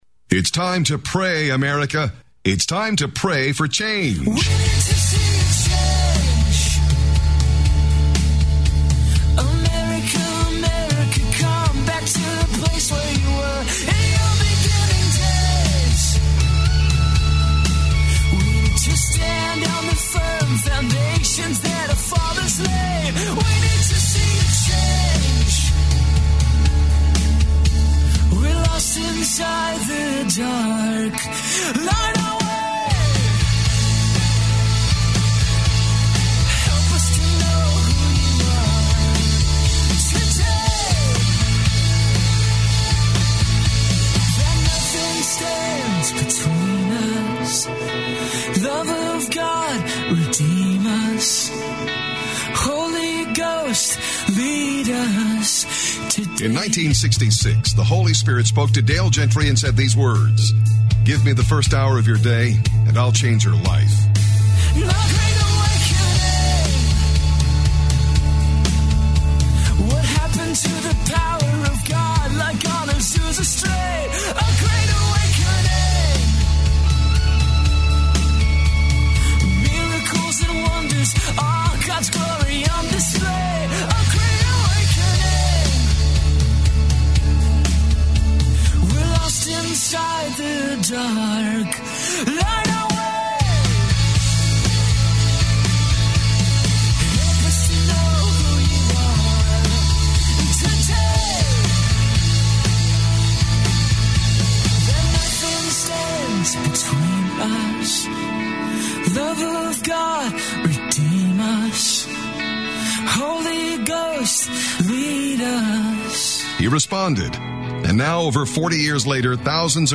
Audio Archive BPN Interviews